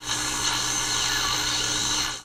ttr_s_ara_csa_radioTune.ogg